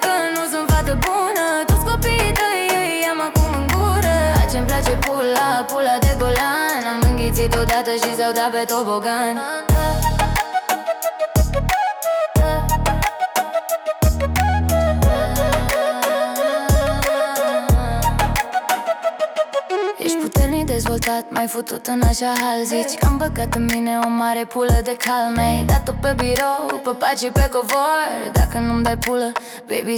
Pop African Afro-Pop
Жанр: Поп музыка